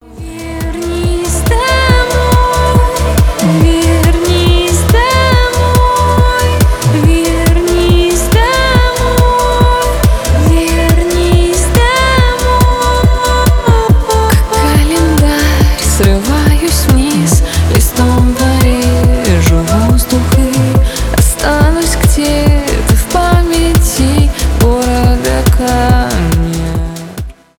поп
альтернатива
indie pop